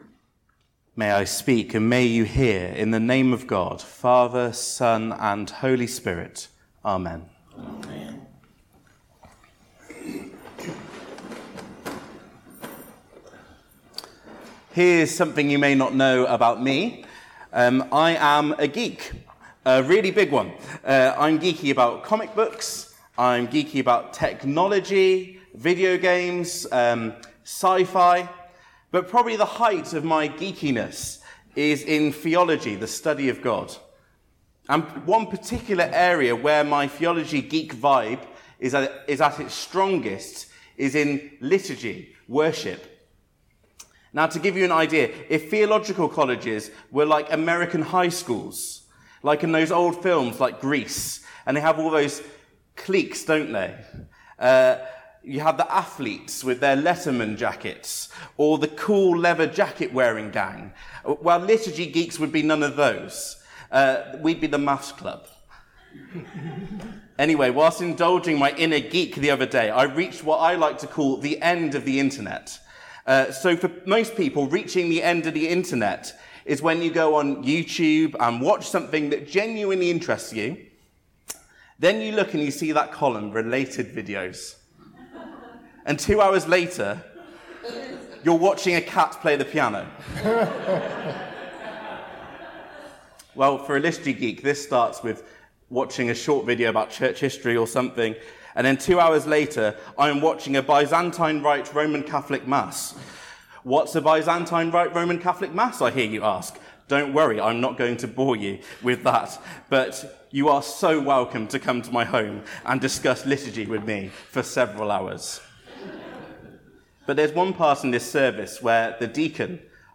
180722 Sunday Service - Denham Parish Church